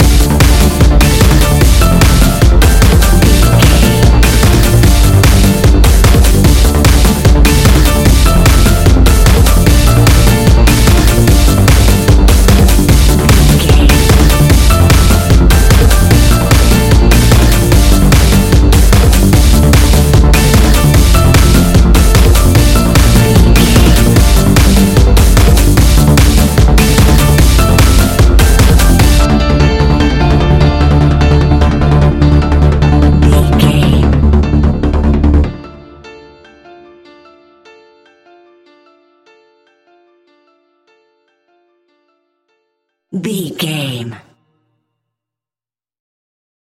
Aeolian/Minor
D
Fast
futuristic
heavy
energetic
uplifting
hypnotic
industrial
drum machine
piano
synthesiser
percussion
acid house
electronic
uptempo
synth leads
synth bass